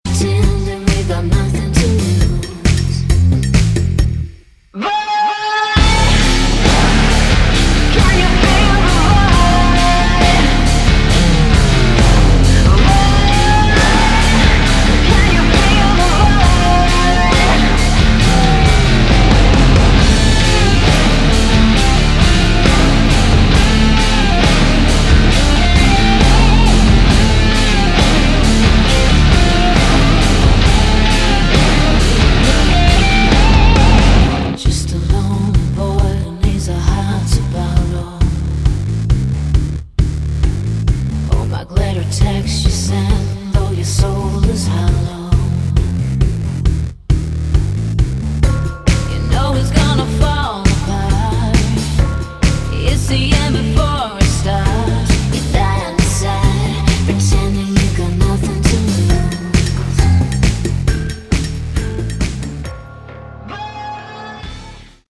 Category: Melodic Rock
vocals, guitars
bass, guitar, keyboards, piano, violin, backing vocals
drums